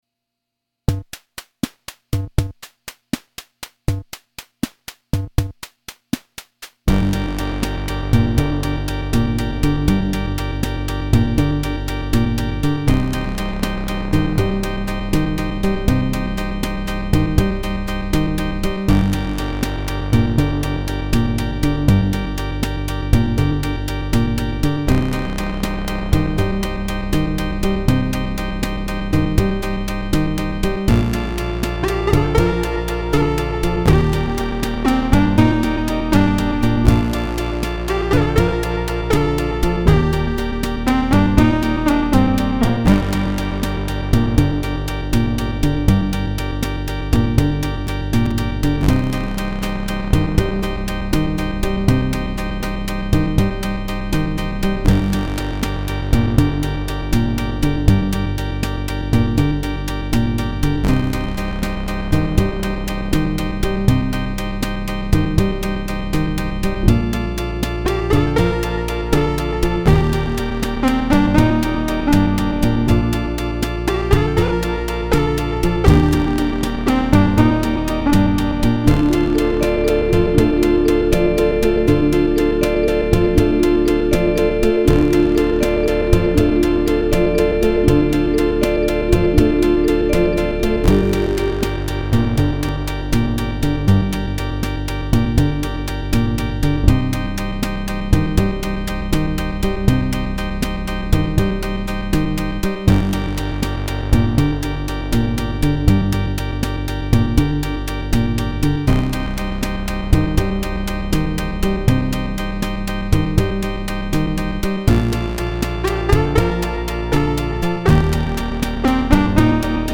This is the first draft for a space balladishy sounding song, I haven't decided on lyrics yet for it, my intention is to add lyrics and clean it up, spice it up and sweeten it up. I had a lot of technical problems trying to record it so I may end up going in a different direction next time I go to work on it, but here's what I made.Â  To record this I used cubase, my not so music friendly Del laptop, a pod line 6 fx thingy, my old casio PT-30 (for drum and bass) and a casio MT-240 (for lead, I put this through the pod).
Filed under: Instrumental Song | Comments (6)
The 8 bit sounding drums and ballad bass line arpegiator are from the PT-30 ballad rhythm and chords.
Oh and the bells are the vibraphone on the MT-240